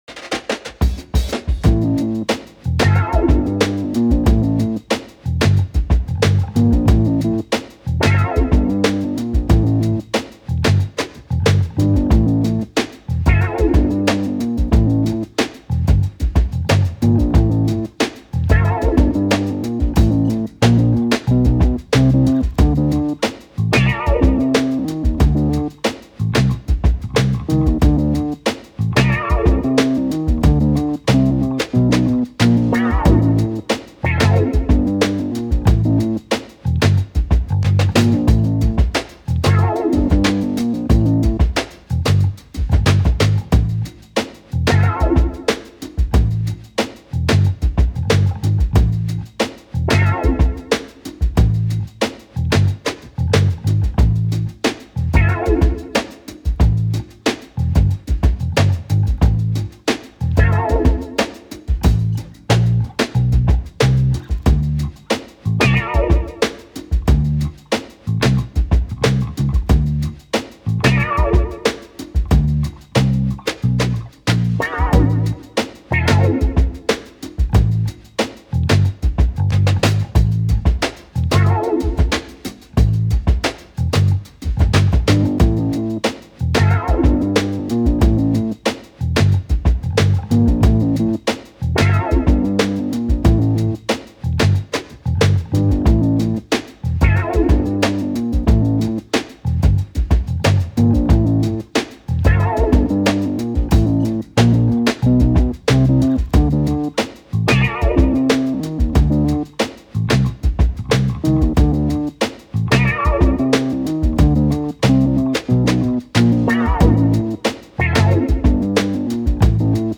Funky grooves with underscores á la spy and agent.